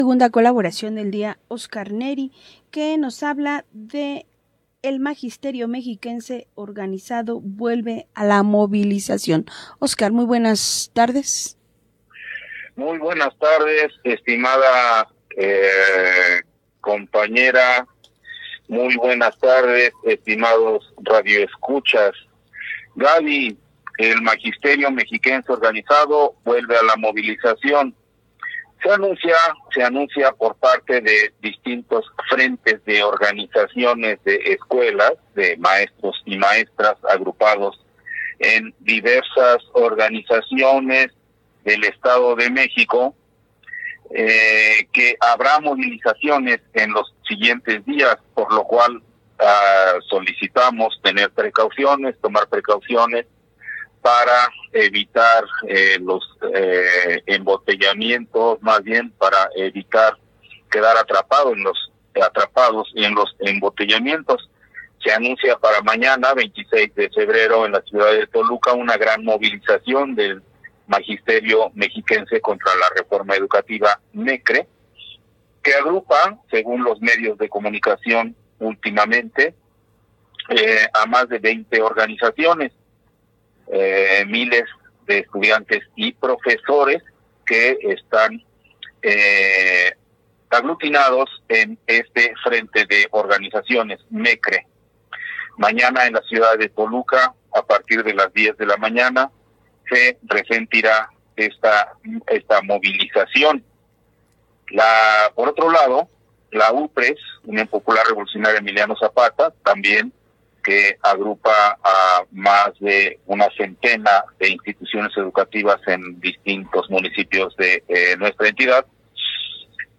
Participación En Neza Radio, el 25 de Febrero 2026 Publicaciones Relacionadas: 15-nov-23 Manifestaciones de Maestros y Defensores de Derechos Más acciones del […]